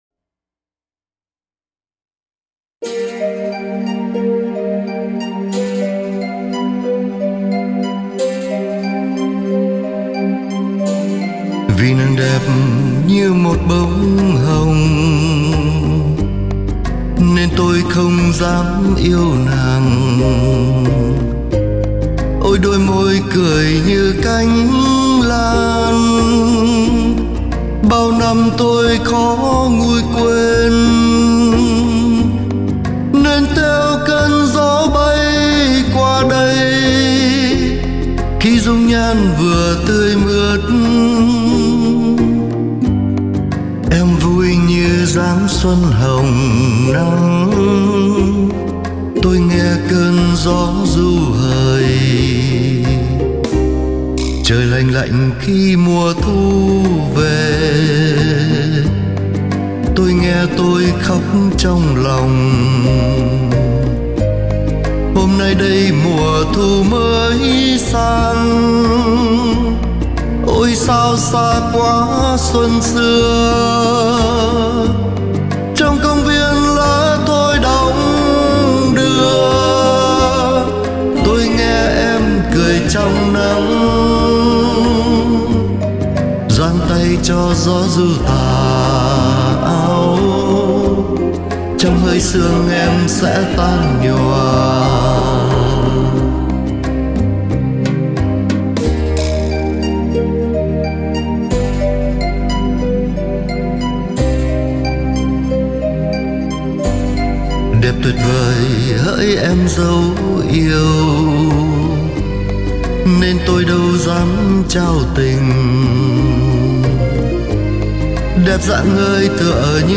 Tiếng hát đặc biệt trầm ấm và ngọt ngào